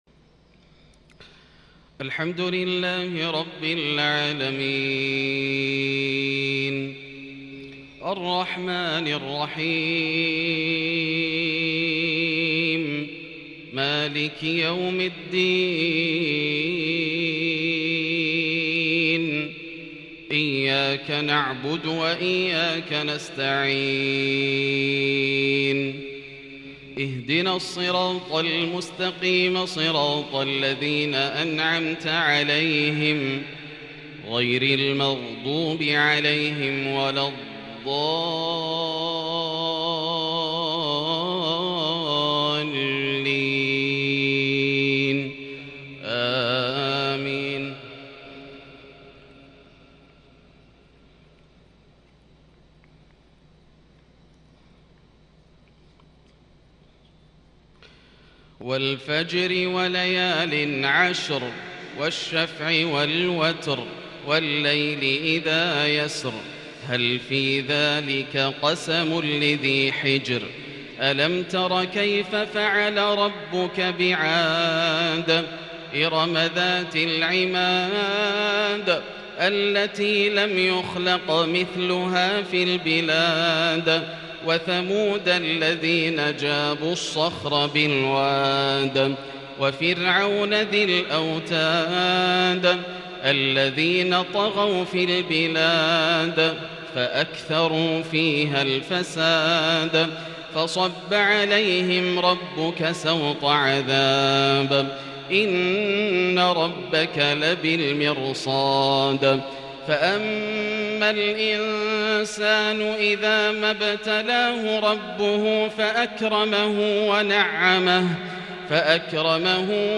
صلاة العشاء ٣-٤-١٤٤٢هـ سورتي الفجر والليل | Isha prayer from Surah AlFajr and AlLail| 18/11/2020 > 1442 🕋 > الفروض - تلاوات الحرمين